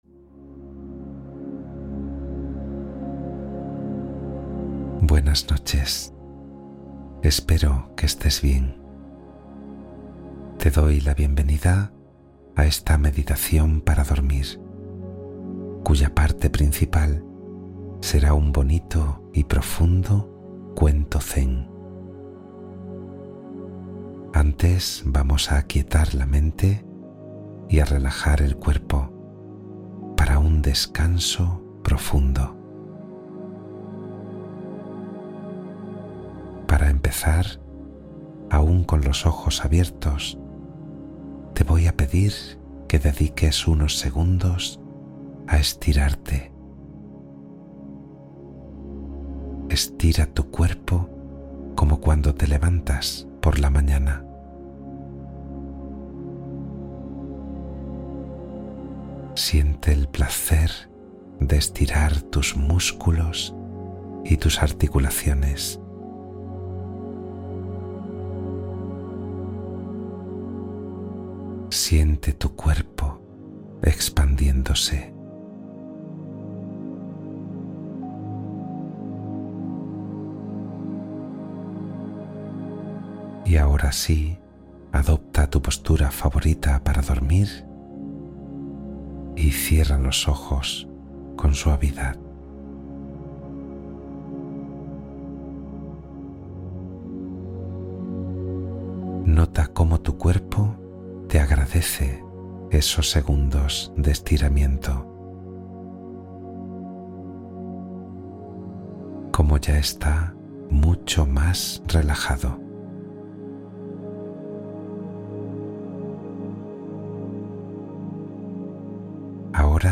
Cuento Zen para Dormir Profundo y Relajación Total